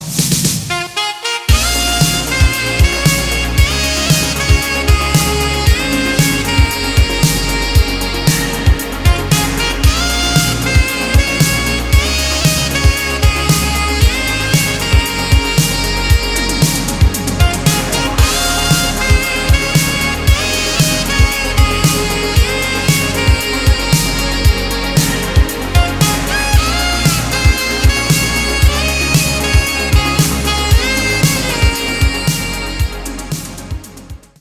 • Качество: 320, Stereo
громкие
без слов
Саксофон
Retrowave
Стиль: retro-/synthwave